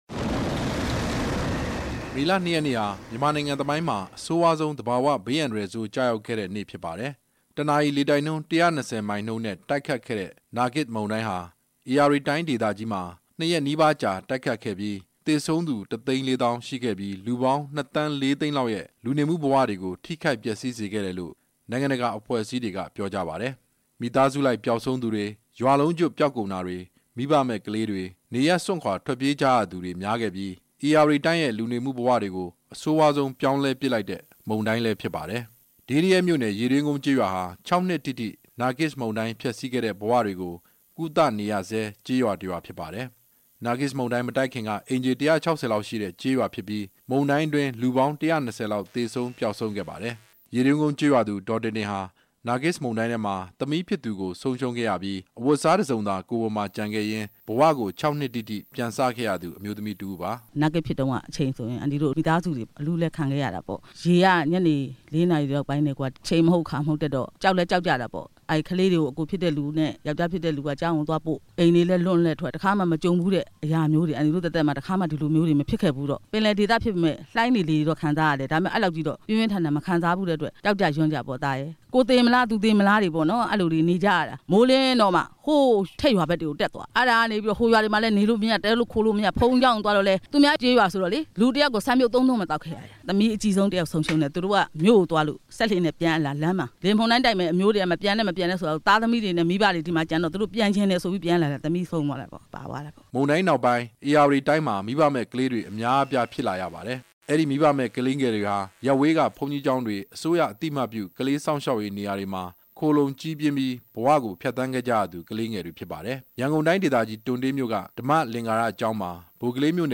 နာဂစ်လေမုန်တိုင်း (၆) နှစ်မြောက် အတွေ့အကြုံ မေးမြန်းချက်